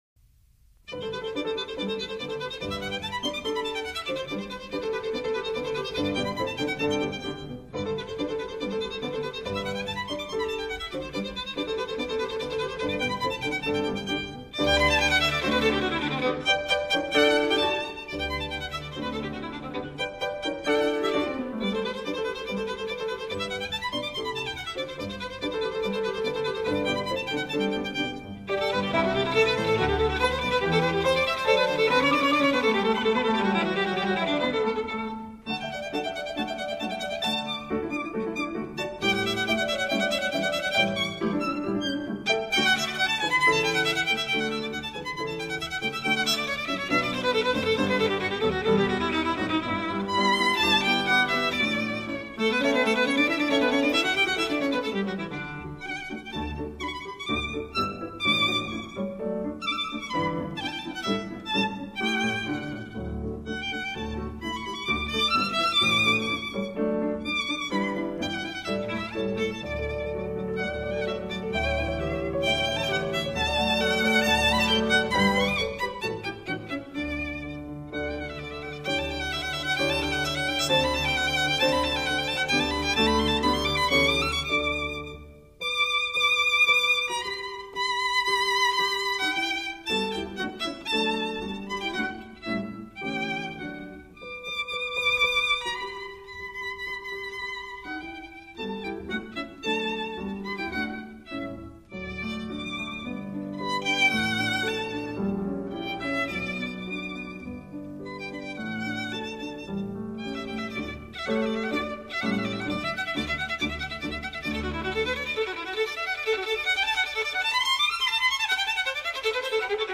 Violin
Piano
小提琴和钢琴配合得恰到好处，祝大家周末愉快，明天送上CD2.